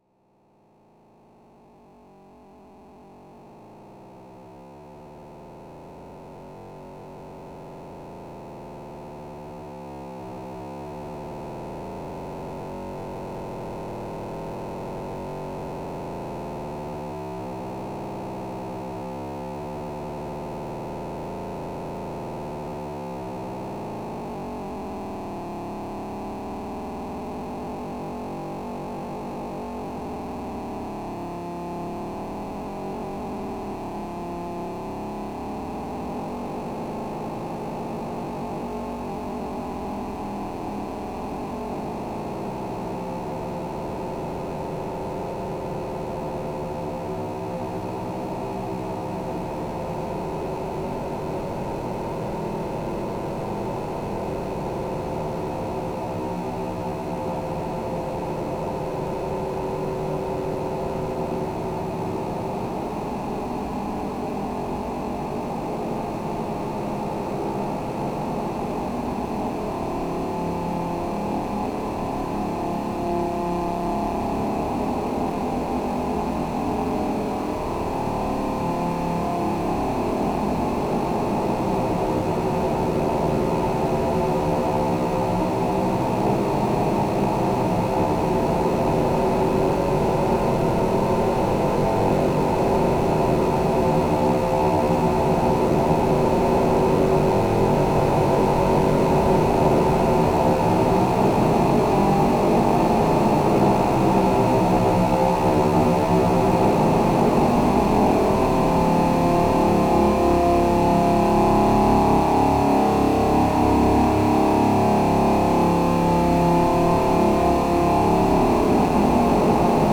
Il ne s'agit pas de science fiction mais bien d'expériences dans l'instant présent avec les sons produits par mes synthétiseurs électroniques.
La grandeur et la majesté d'une nébuleuse prête à donner un nouveau monde. C'est violent, c'est chaotique et douloureux, mais c'est beau comme un accouchement.